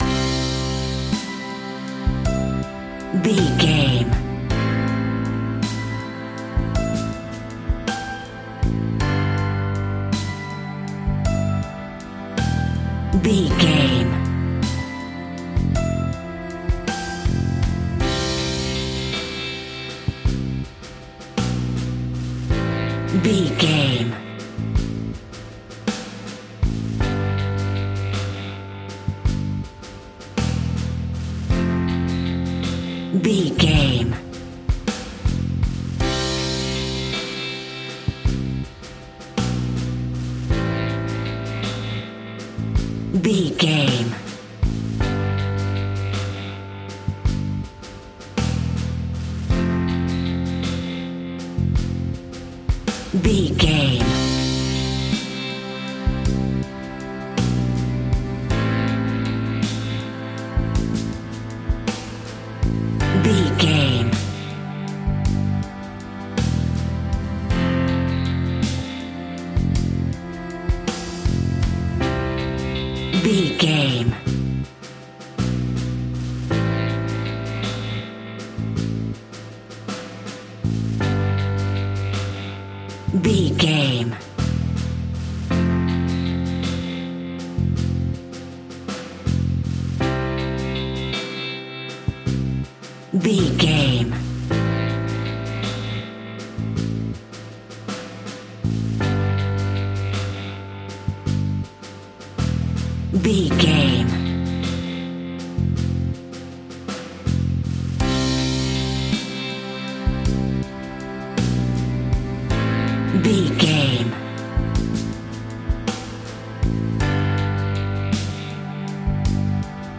Indie Anthem Pop Rock.
Ionian/Major
indie rock
sunshine pop music
drums
bass guitar
electric guitar
piano
hammond organ